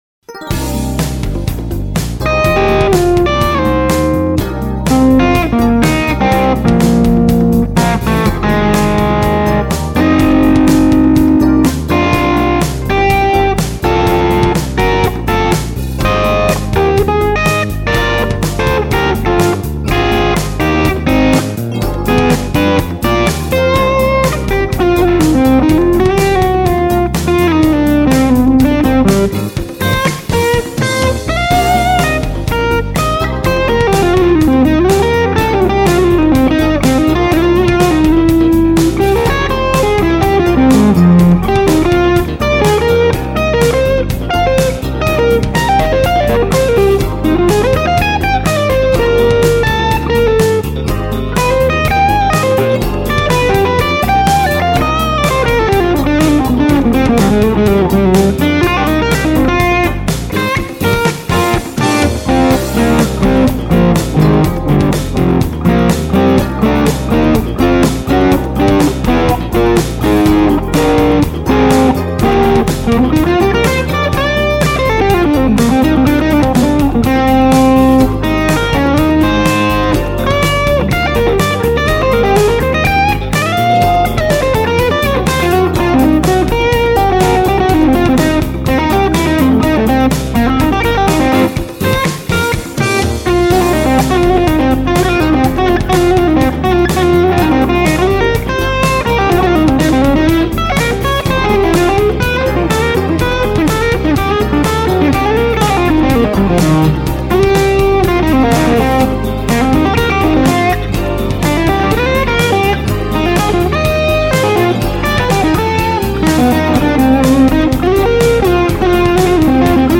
I multimiced with a Royer R121 and SM57.
The only negative was that I sat at the DAW while recording. The amp was 10 feet away and pointed 90 degrees from where I was.
Chain was Carruthers CSA (finally new strings Mangen pure nickel 11s) on bridge hum, into Bludo Music Man 6L6 Skyline non HRM, into loopalator and 2290 set to 117ms 55% 1% feedback, into Glaswerks 2X12 w/Celestion G1265 speakers.
No post processing other than a tiny bit of verb and about 2db boost from 8K to 11K to add a little "air".
Sounds "Muscular" not "Molluskular"!
To me the guitar sat a bit too forward in the mix.